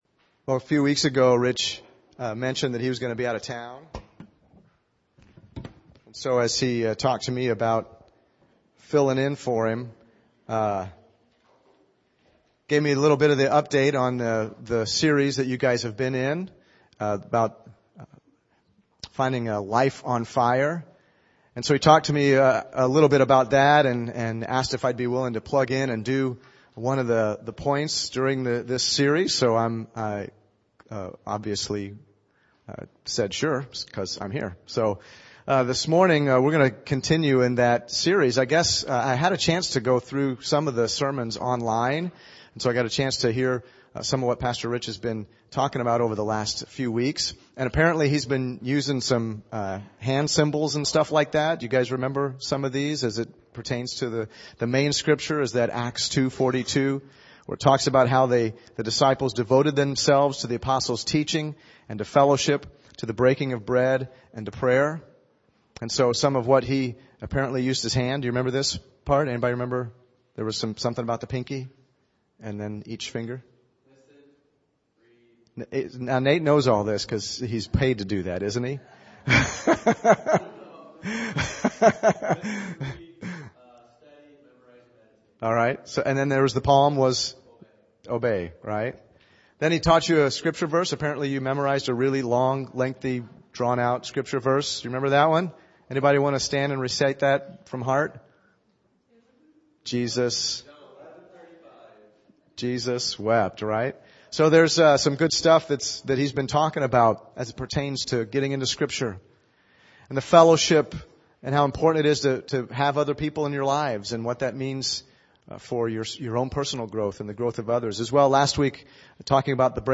Sunday Messages
(Message begins @ 3:22) Podcast: Play in new window | Download Subscribe: Apple Podcasts | Android | RSS